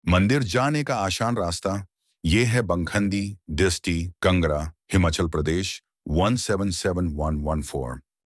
ElevenLabs_Text_to_Speech_audio.mp3